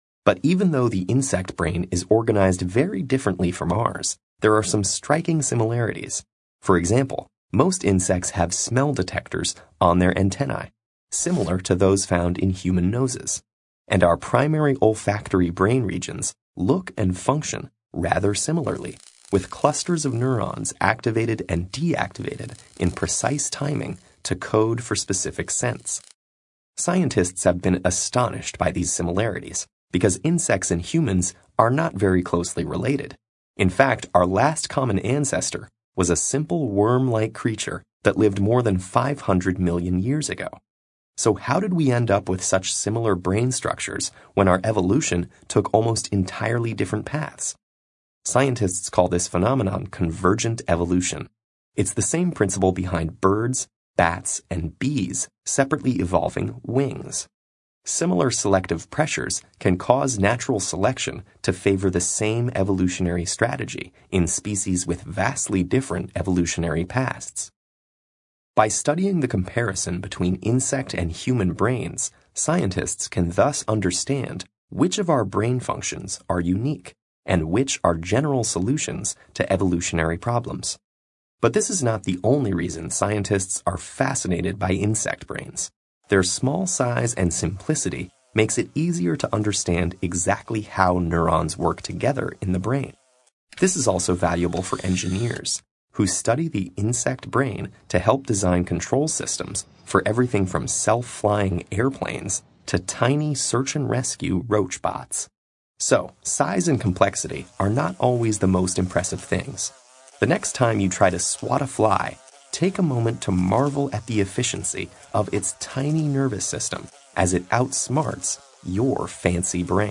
TED演讲:为什么昆虫的大脑如此神奇(2) 听力文件下载—在线英语听力室